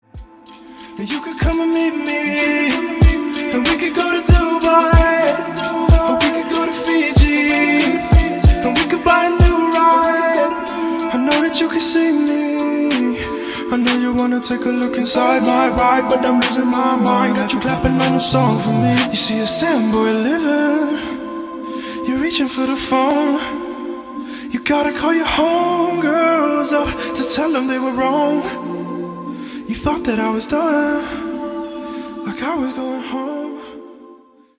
• Качество: 192, Stereo
красивый мужской голос
спокойные
Trap
RnB